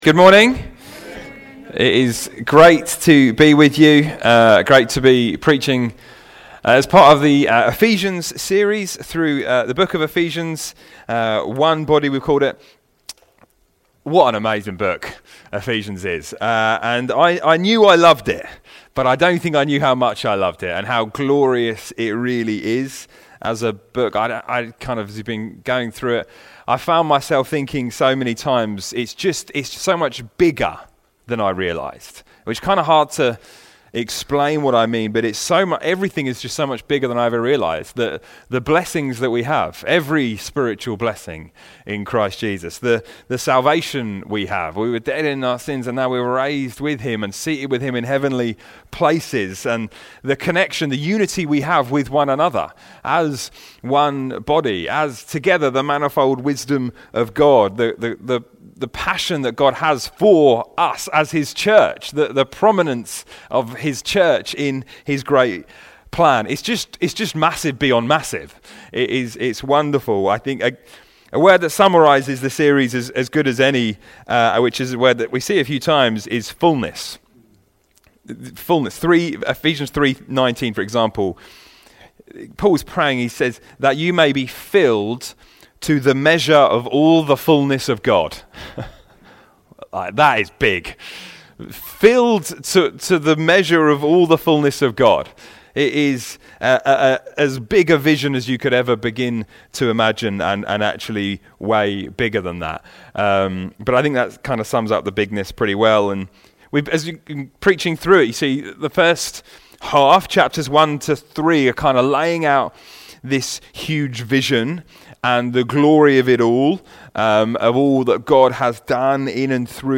Sunday Teaching Be Filled with the Spirit (Havant)